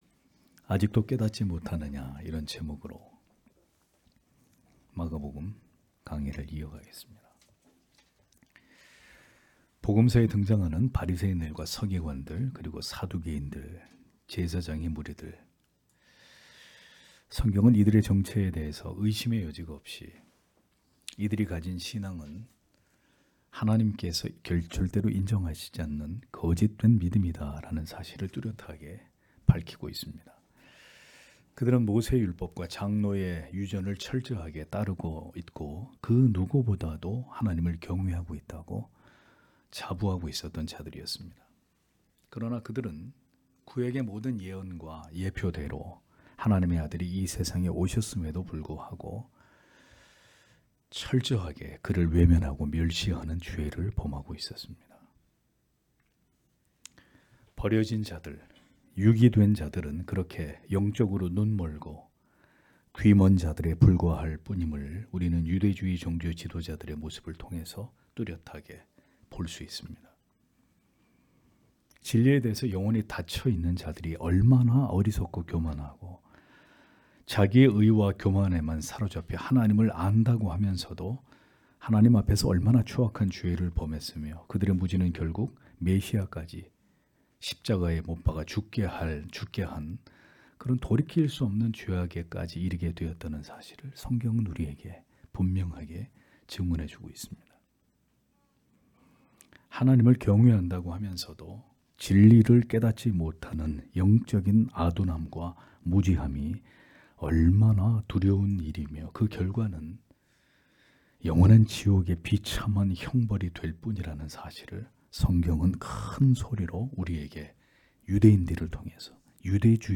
주일오전예배 - [마가복음 강해 30] 아직도 깨닫지 못하느냐 (막 8장 11-21절)